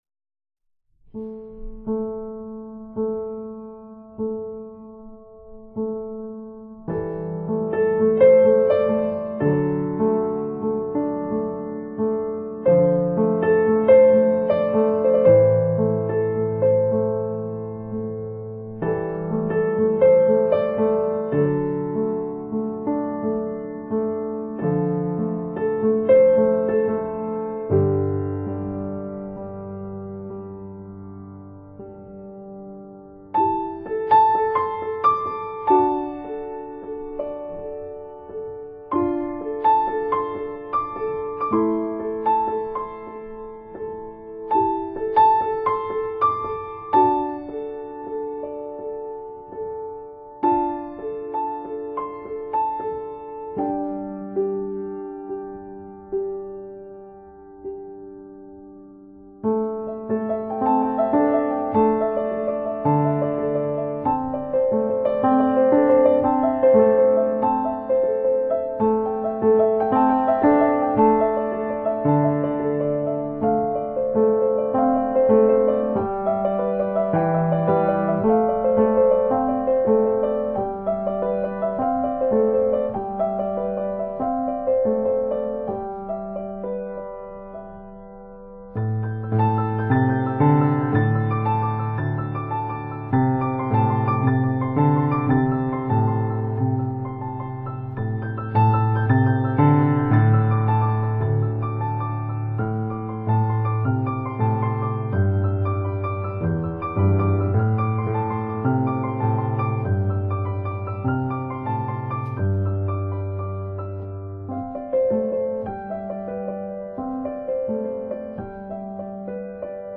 搖身一變成為新世紀鋼琴意象的代表。
清清澹澹的琴音獻給每一顆豐富的心靈。